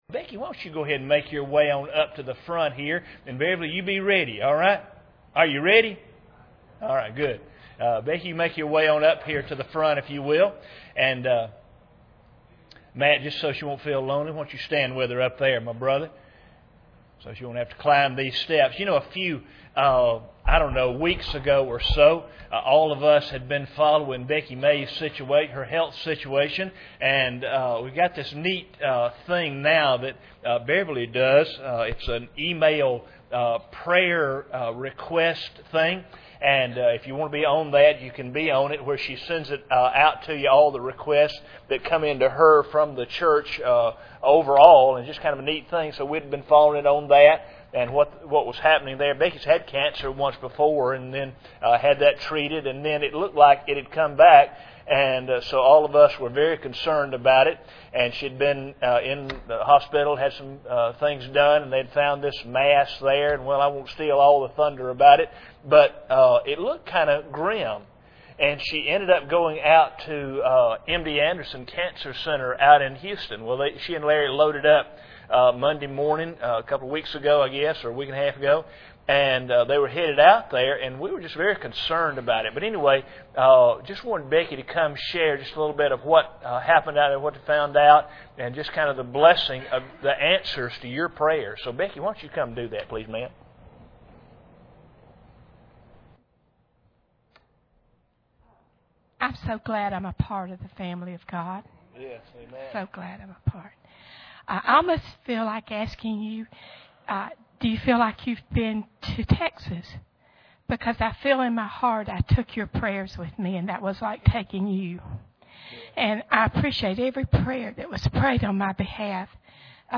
Testimonies
General Service Type: Sunday Evening Preacher